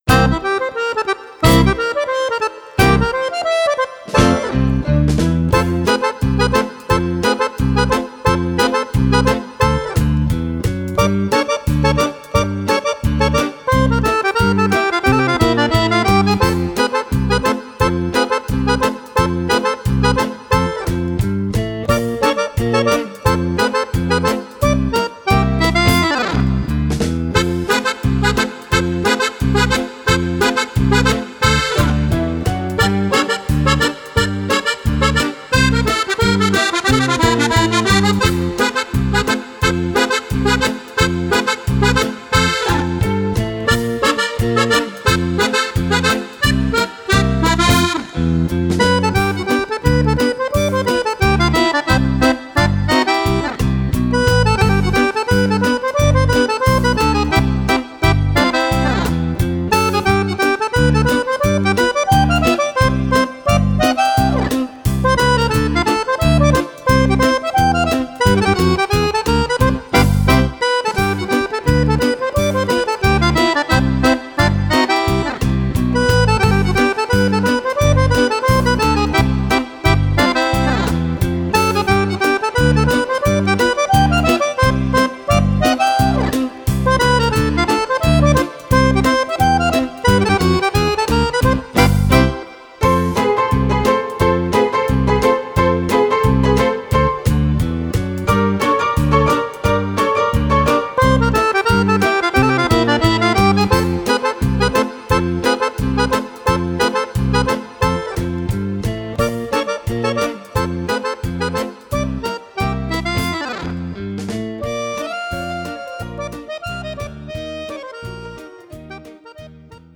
Fox
e 12 ballabili per Fisarmonica solista